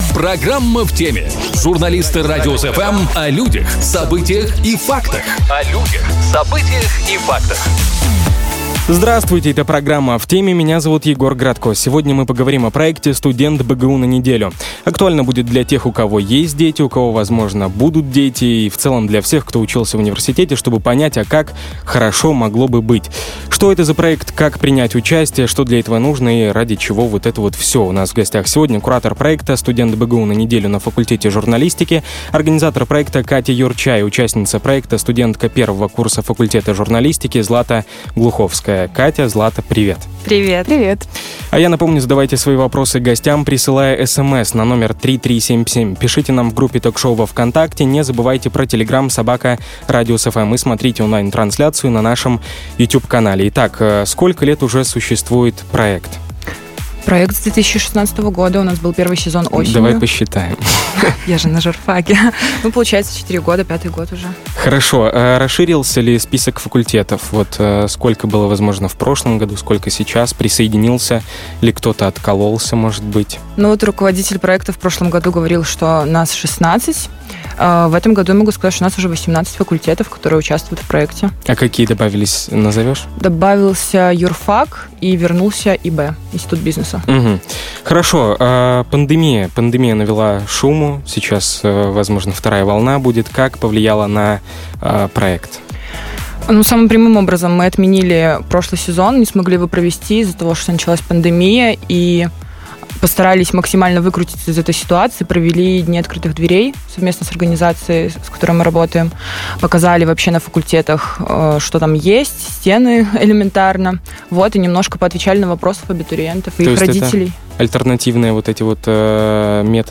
Студент БГУ на неделю | Шоу с толком | Радиус-FM